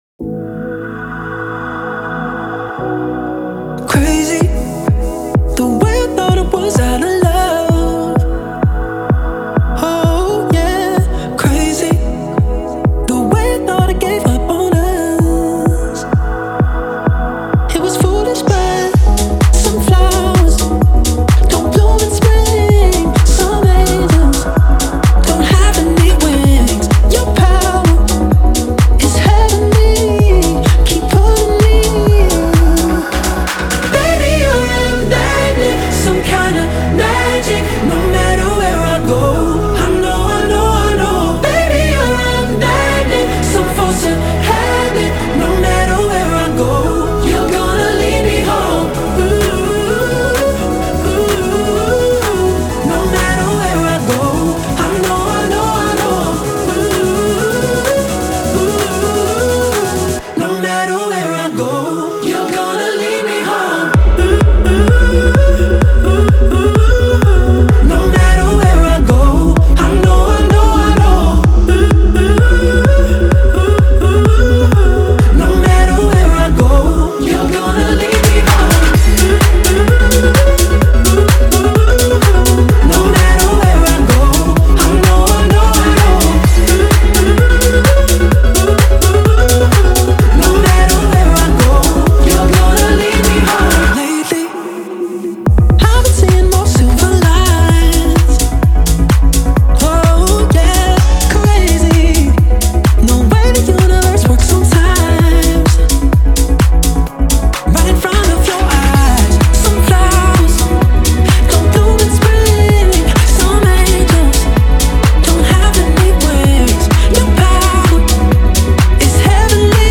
• Жанр: Dance, Electronic